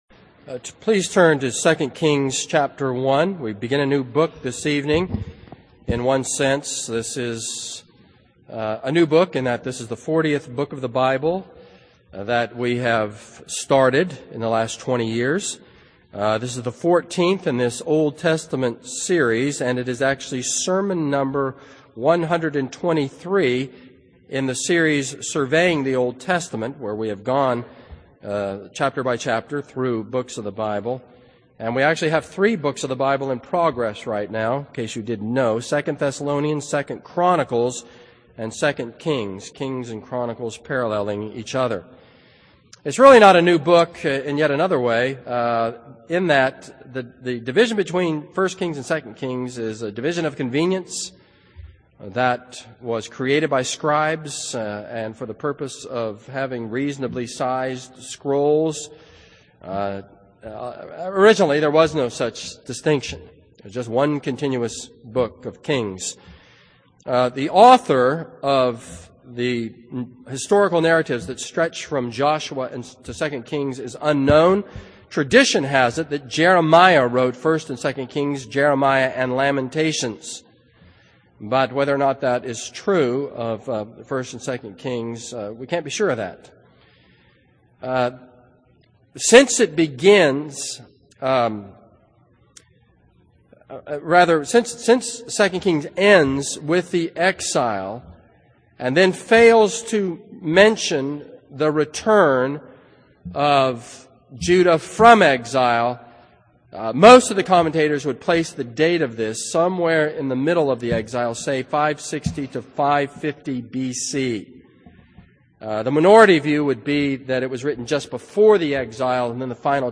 This is a sermon on 2 Kings 1.